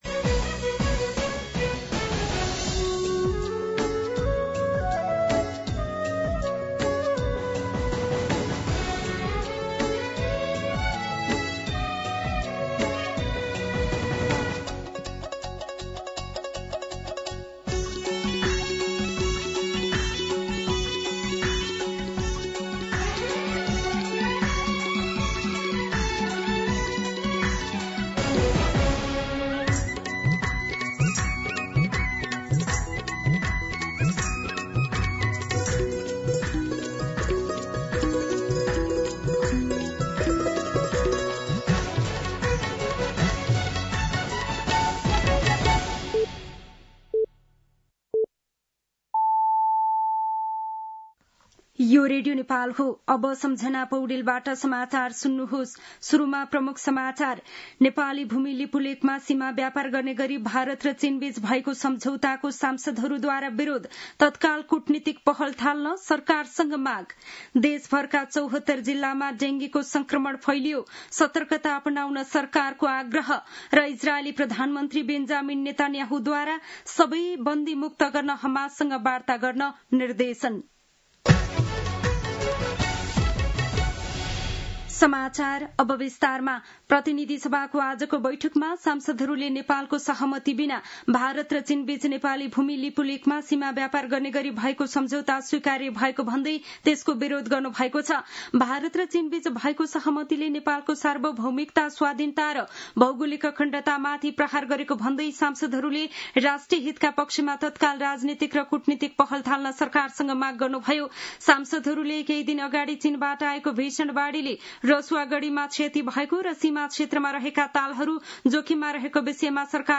दिउँसो ३ बजेको नेपाली समाचार : ६ भदौ , २०८२
3-pm-Nepali-News-1-2.mp3